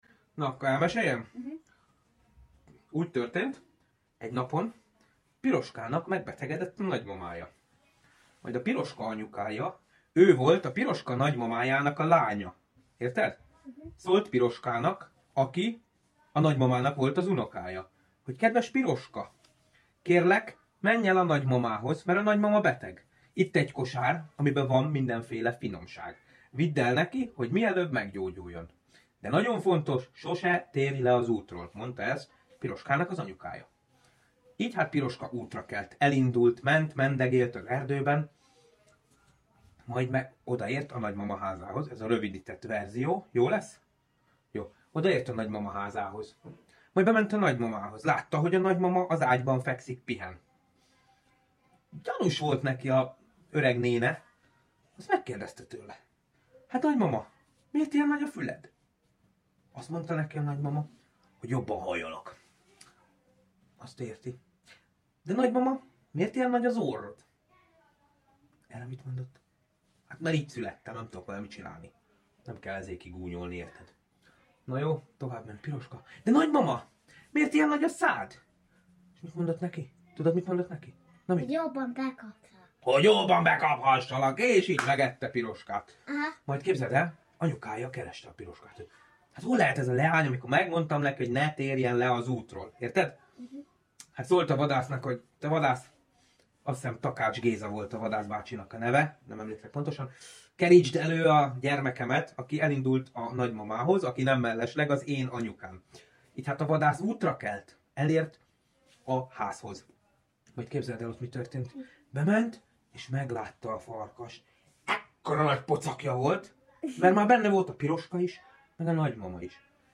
Mp3 Sound Effect Piroska és a farkas hangos. könyv.